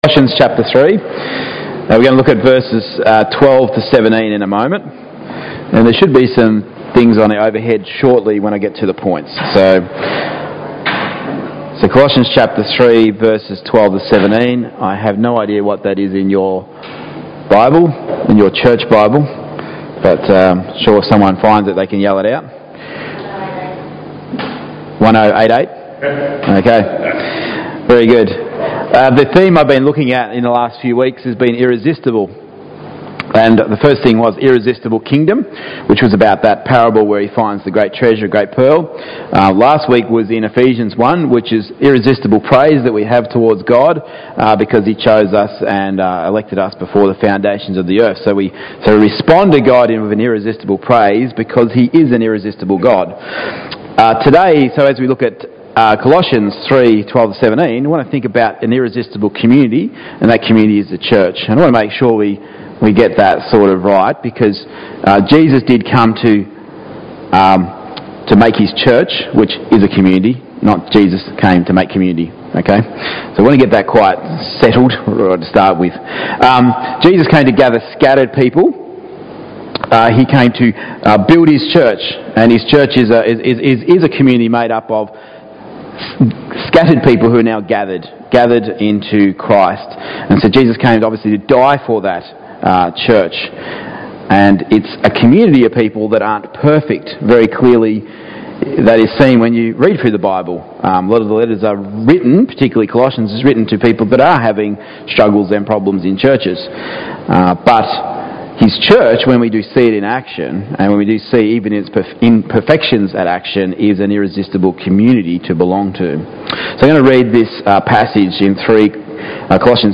Service Type: Morning Gatherings